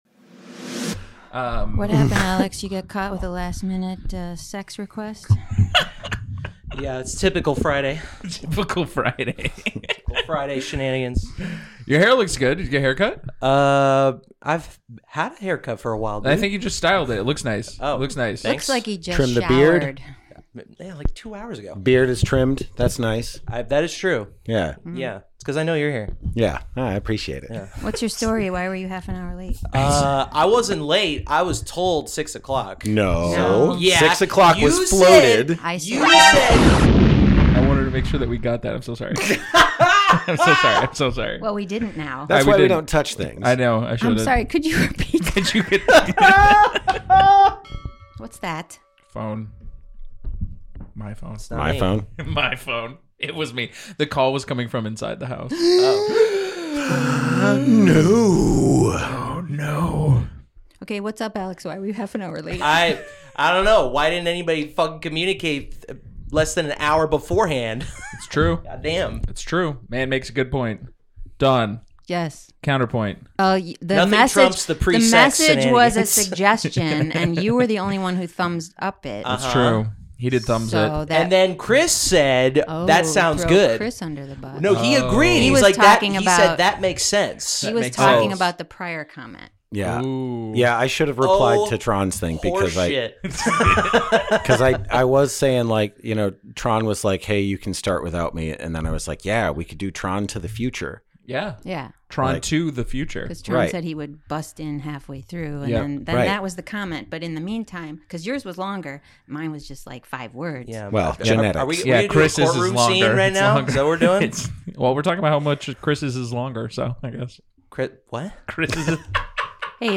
An hour - more or less - of friendly discussion, improvised scenes, characters and riffs, brought to you by JEST Improv!